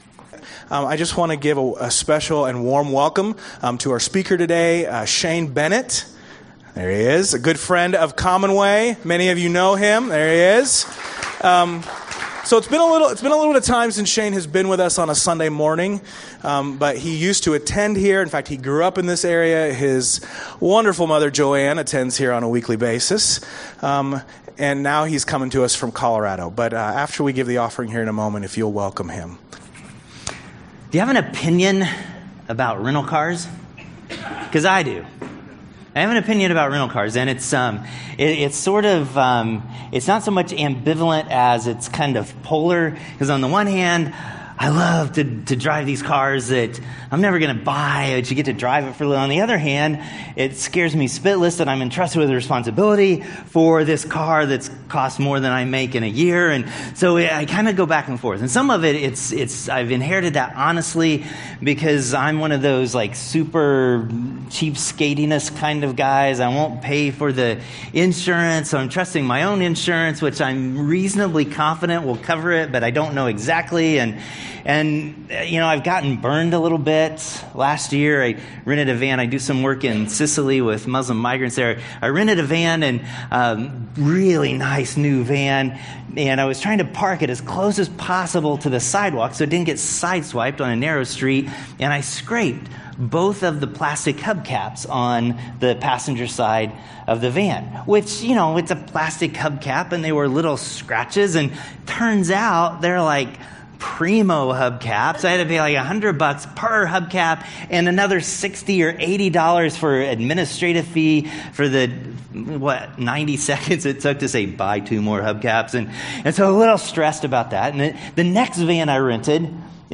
A message from the series "Standalone Messages (2019)."